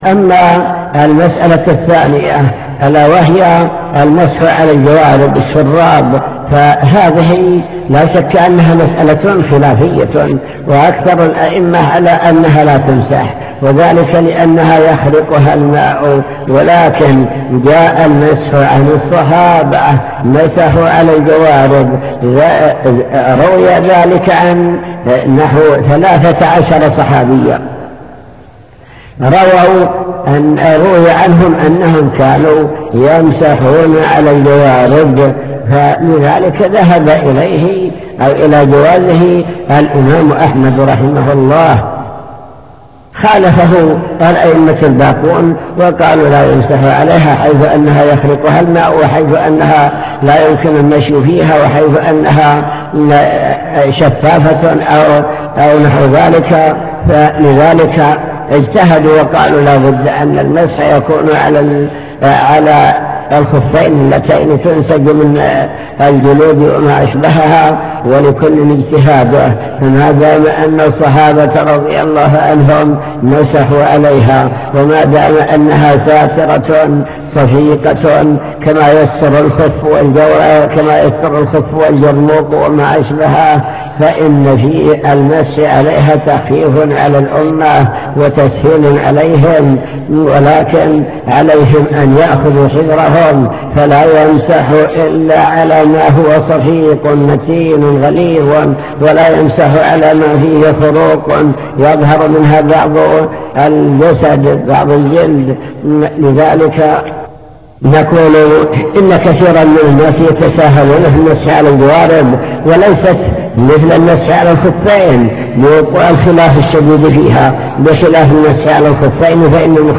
المكتبة الصوتية  تسجيلات - محاضرات ودروس  الافتراق والاختلاف الكلام عن الخلاف وأسبابه